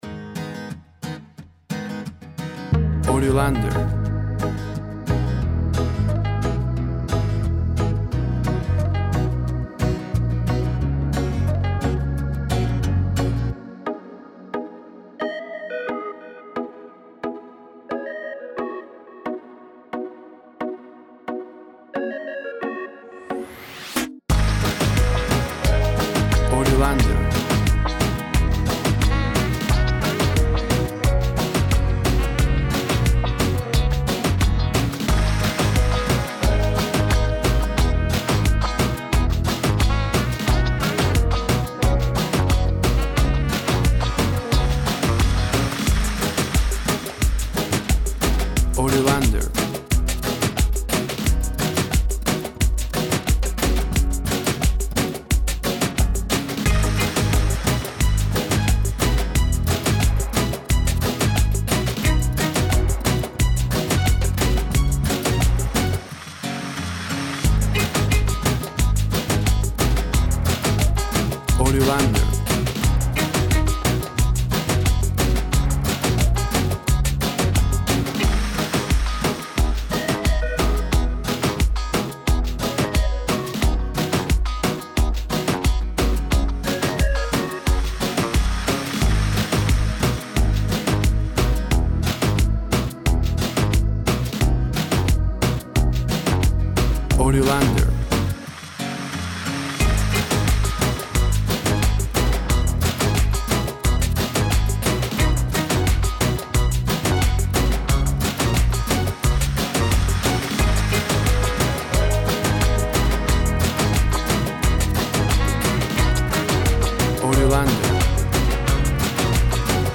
WAV Sample Rate 24-Bit Stereo, 44.1 kHz
Tempo (BPM) 90